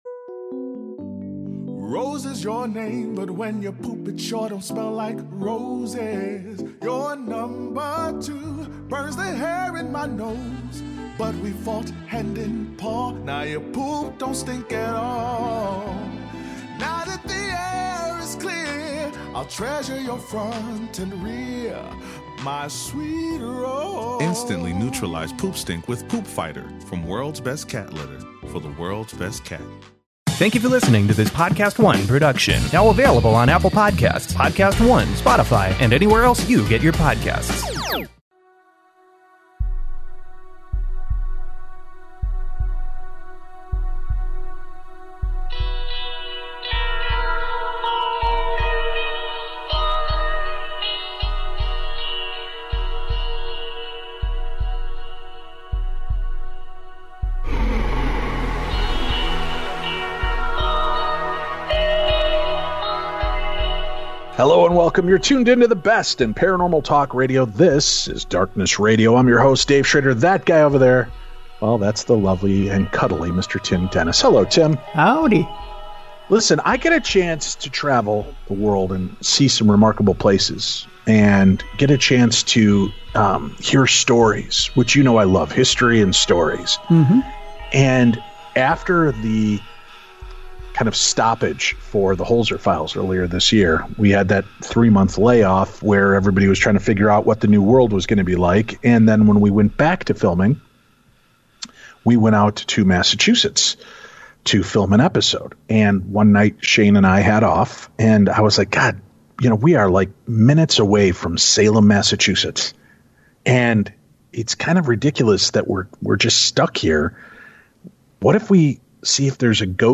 Hello and welcome you're tuned into the best in paranormal talk radio